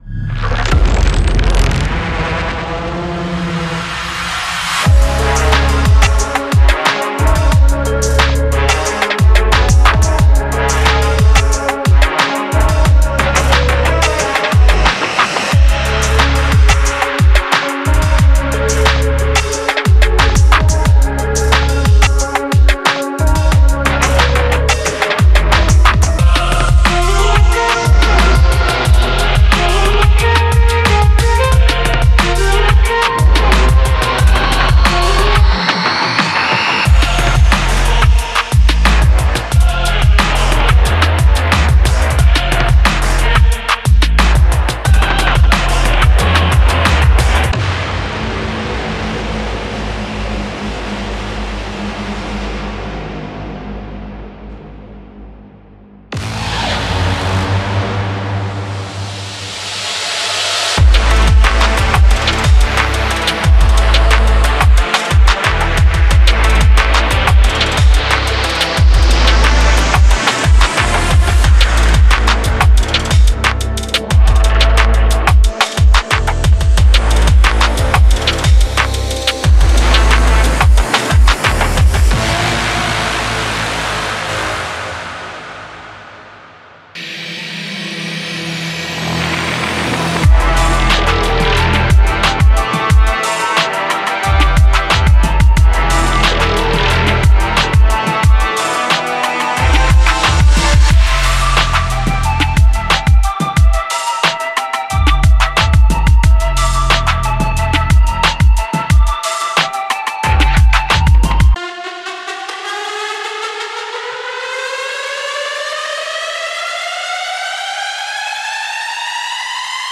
Genre:Cinematic
ダークトラップおよびオーケストラ系ビートメイキング
デモサウンドはコチラ↓